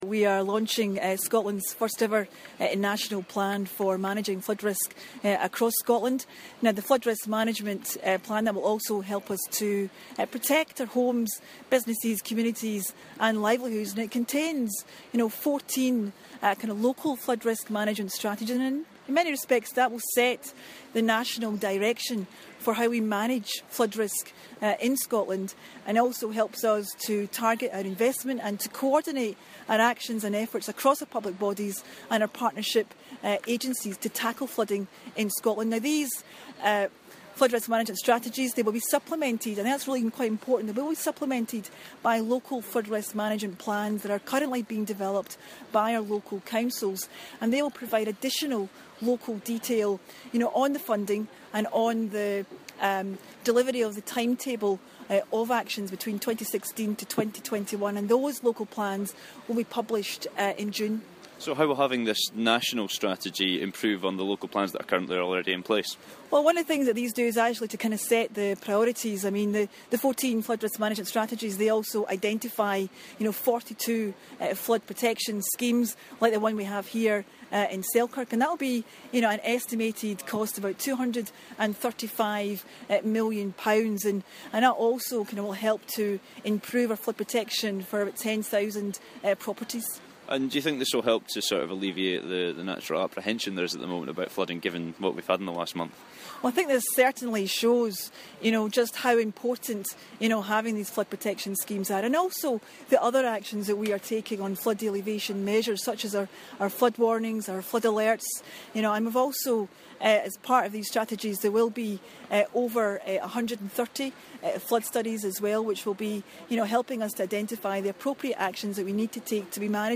Environment Minister Aileen McLeod at the launch of the Scottish Government Flood Risk Management Plan in Selkirk: